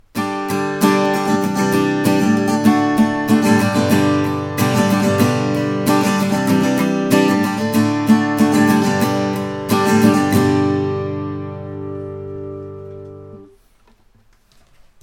Bocote OM, V-Class bracing, fan fretted. Soundclip.
Downside for me is this guitar has a quite mid-oriented sound.
Listen to the first clip and hear how my customer makes it sound in an improvisation.